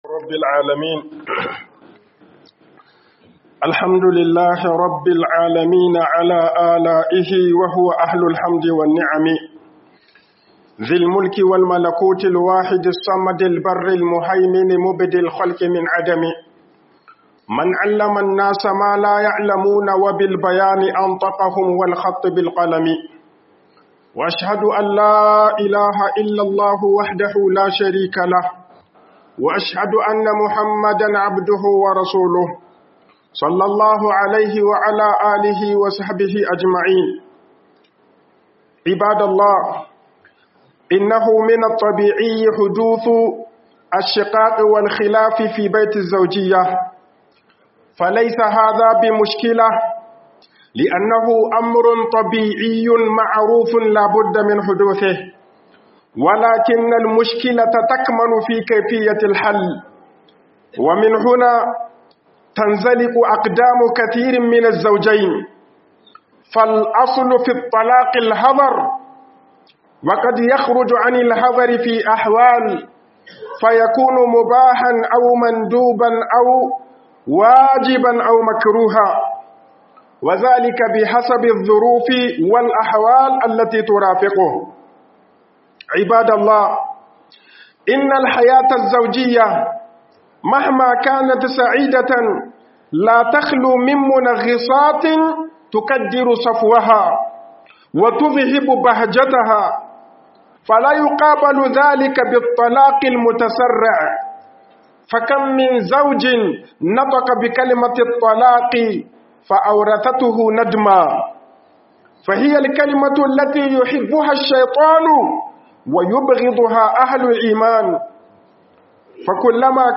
Yawaitar Sakin Aure A Cikin Al'ummar Musulmai - HUƊUBOBIN JUMA'A